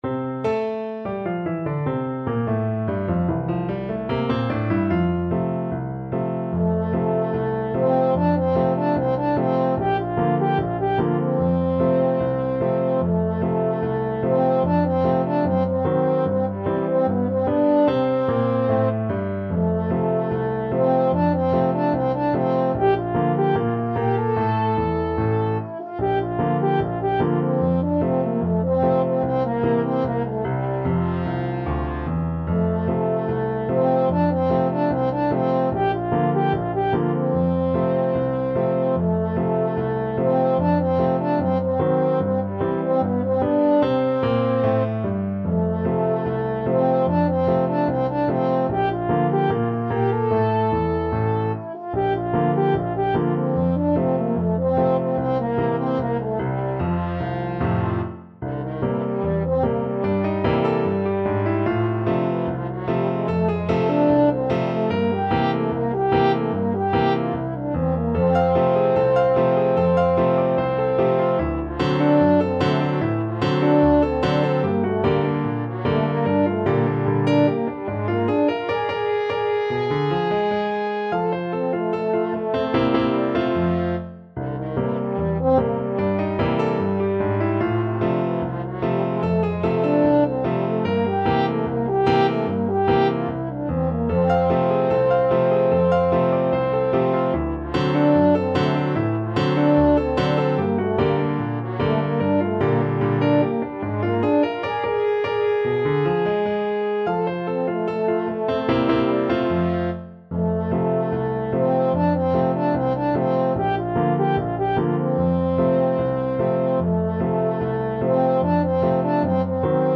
French Horn
F major (Sounding Pitch) C major (French Horn in F) (View more F major Music for French Horn )
Not Fast = 74
2/4 (View more 2/4 Music)